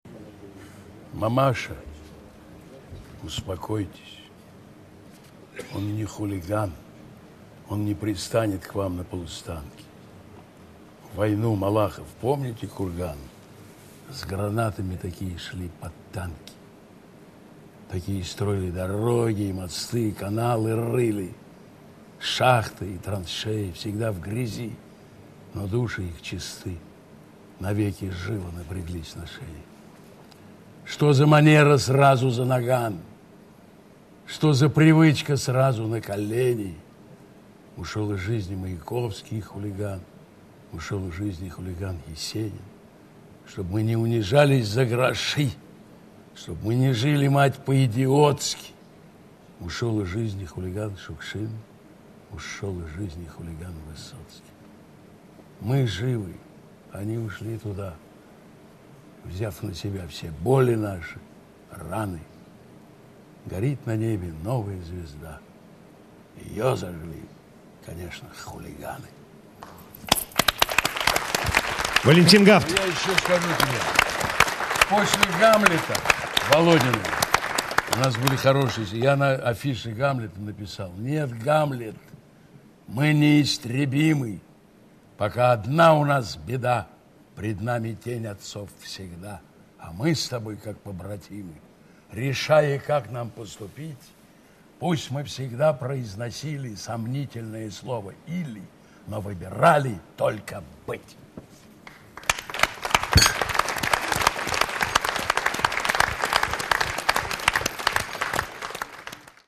Несколько стихотворений в исполнении автора: